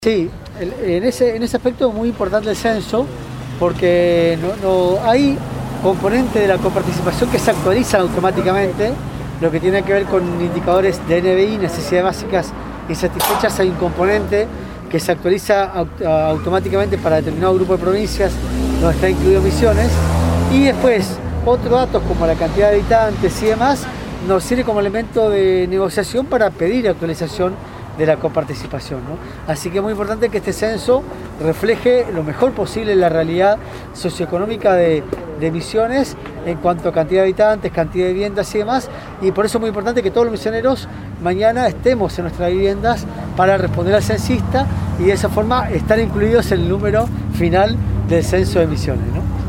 En ocasión de la inauguración de la segunda Sala Digital del NENI 2014, en charla con los medios de prensa el ministro de Hacienda de la Provincia de Misiones Adolfo Safrán relató la incidencia e importancia de que en este Censo Nacional 2022 la provincia refleje los números exactos instando a la población a estar en sus viviendas y esperar la llegada de los censistas.